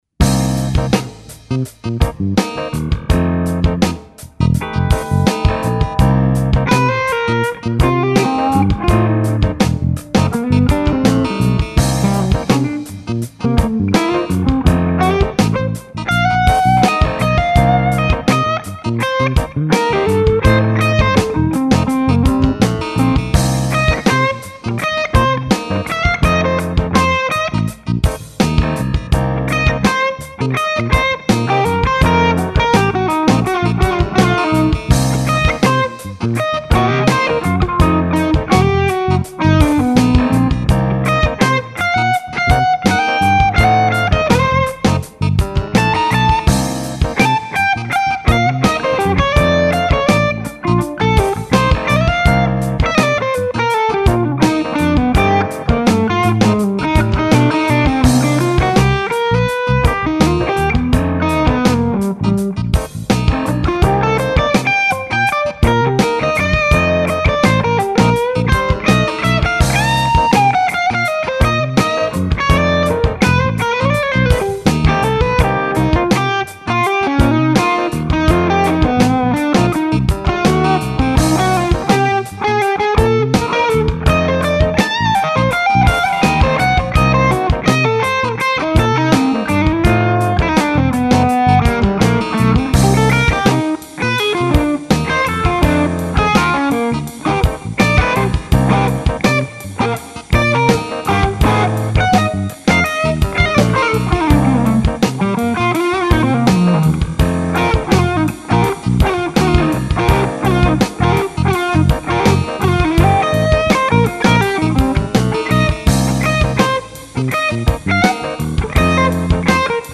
I pulled the R121 back about 4 inches. Slight mid phasing happens, but it is a different effect.